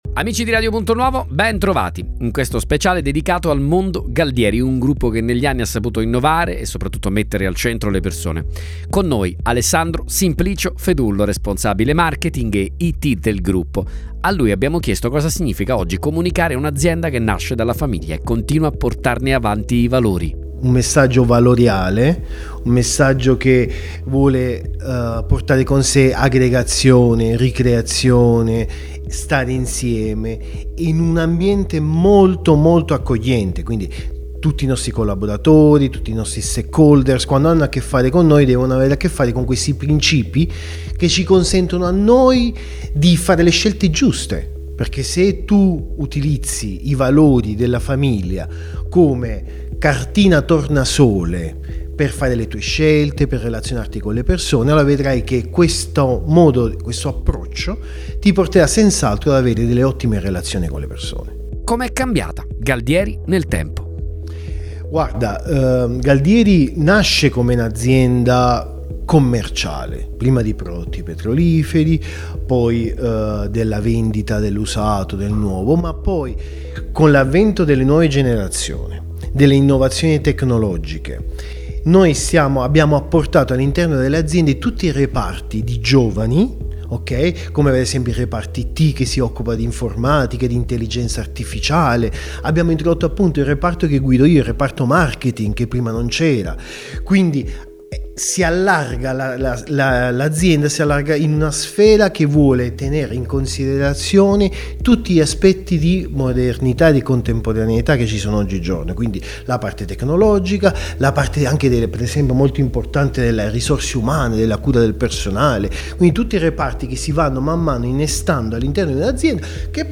Il Gruppo Galdieri a Radio Punto Nuovo: “La Famiglia è la nostra dimensione”.